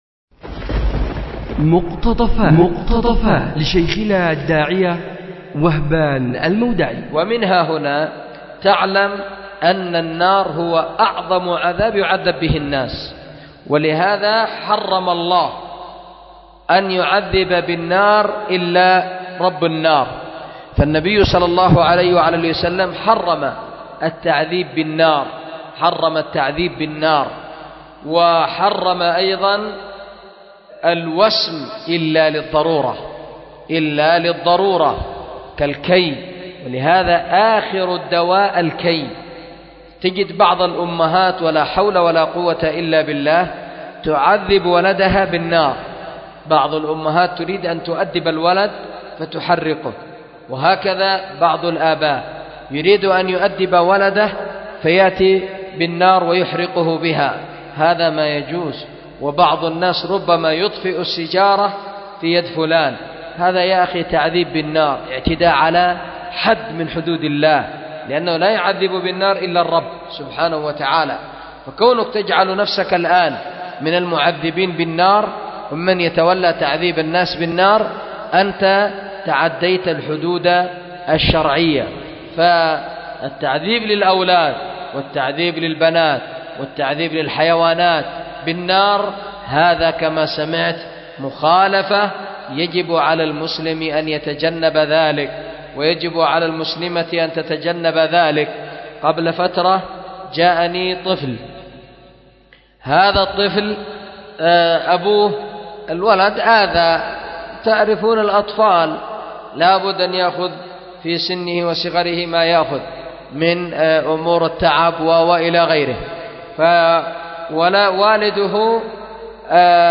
أُلقي بدار الحديث للعلوم الشرعية بمسجد ذي النورين ـ اليمن ـ ذمار 1444هـ